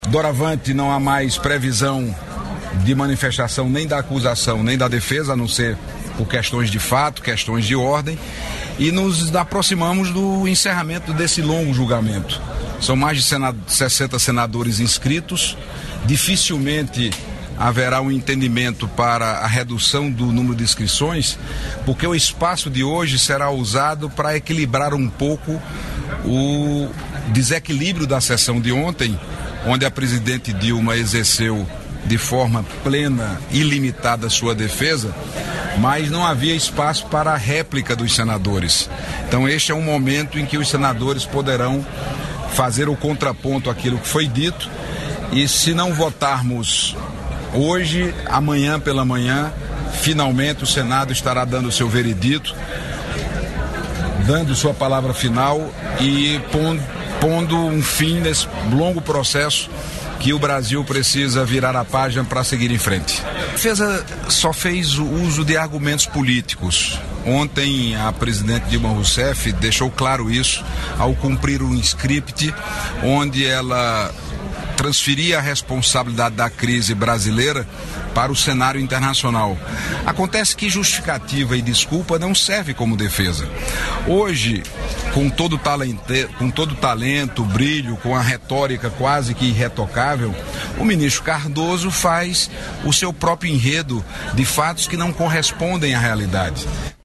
O senador Cássio Cunha Lima (PSDB-PB) disse que os senadores vão aproveitar o tempo que terão para suas manifestações, nesta terça-feira (30), para compensar a falta de réplica durante questionamentos a Dilma Rousseff na segunda-feira (29). Em entrevista à imprensa, o senador tucano lembrou que a presidente afastada exerceu sua defesa de forma plena e ilimitada, sem que os questionadores tivessem direito a réplica.